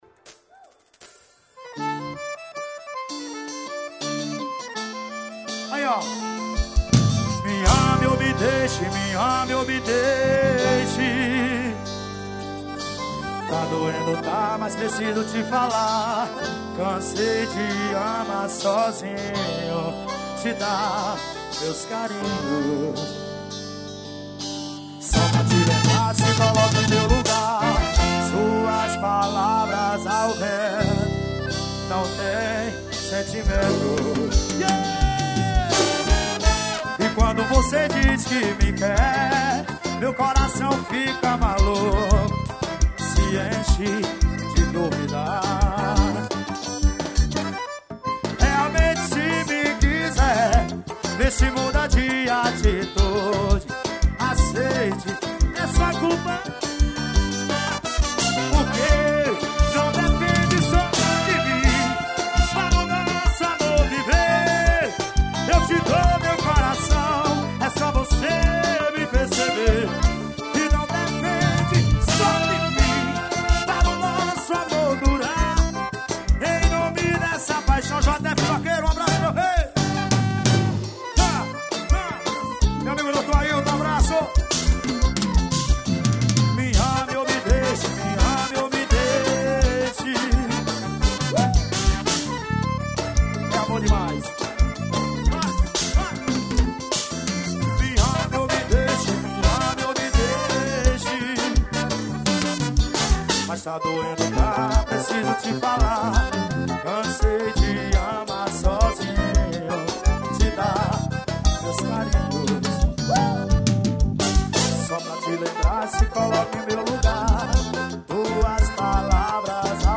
Ao vivo em São Bento-PB.